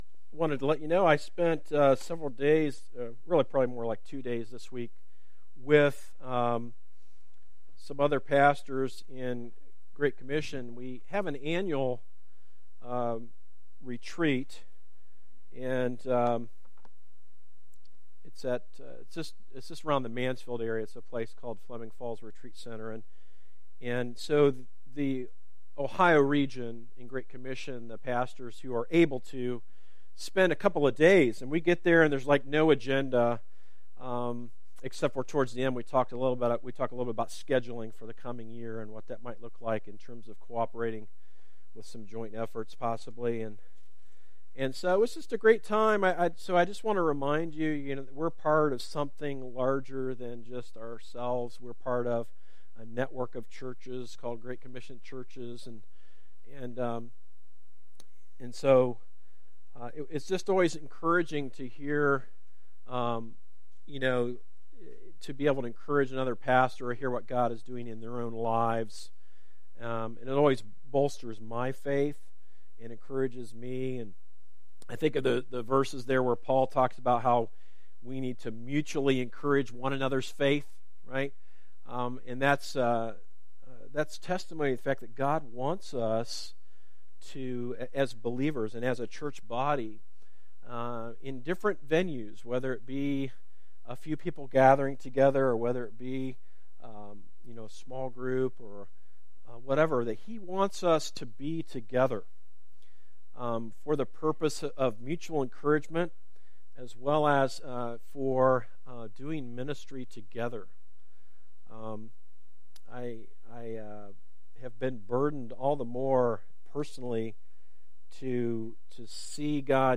Sermons - series archive - Darby Creek Church - Galloway, OH